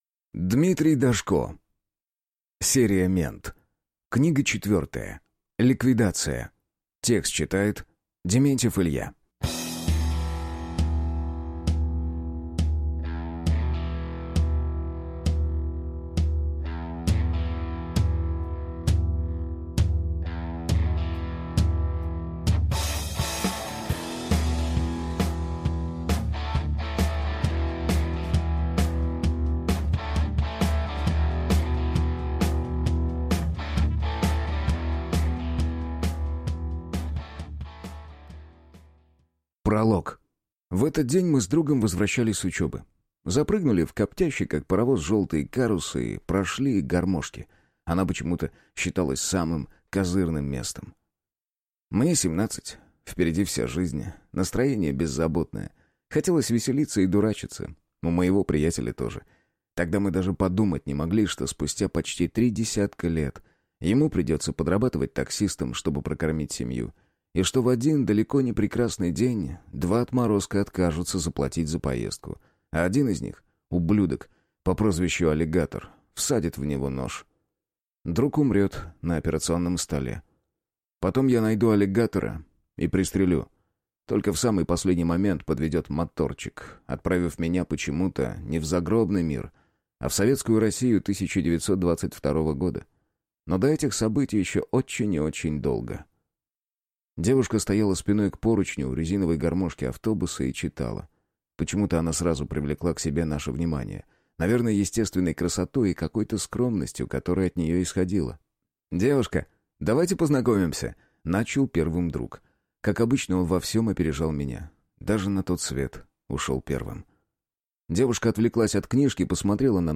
Аудиокнига Мент. Ликвидация | Библиотека аудиокниг
Прослушать и бесплатно скачать фрагмент аудиокниги